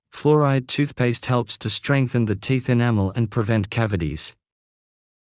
ﾌﾛｰﾗｲﾄﾞ ﾄｩｰｽﾍﾟｰｽﾄ ﾍﾙﾌﾟｽ ﾄｩｰ ｽﾄﾚﾝｸﾞｽﾝ ｻﾞ ﾃｨｰｽ ｴﾅﾒﾙ ｴﾝﾄﾞ ﾌﾟﾘｳﾞｪﾝﾄ ｷｬｳﾞｨﾃｨｽﾞ